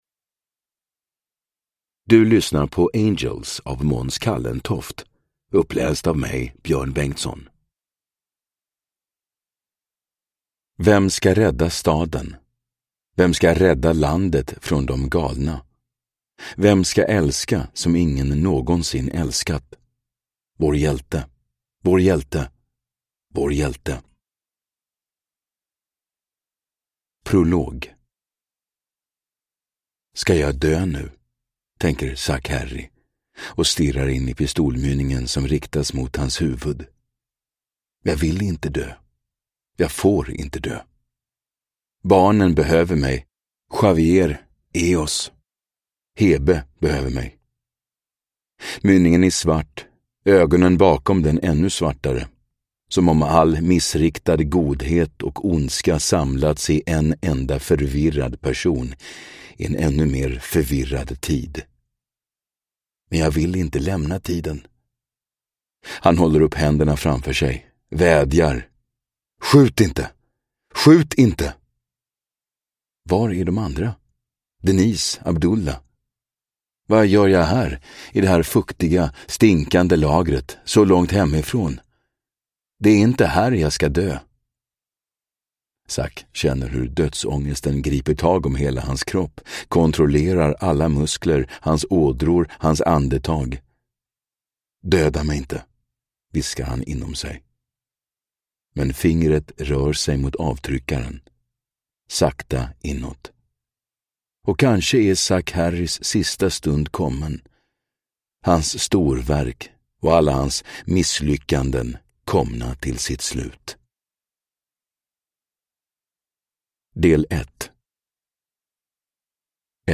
Angelz – Ljudbok
Deckare & spänning Njut av en bra bok